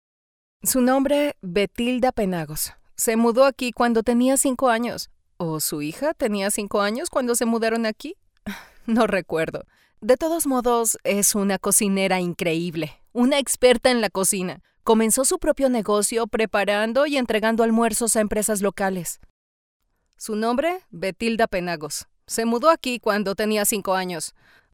Neutral Spanish voice overs.
locutora de español neutro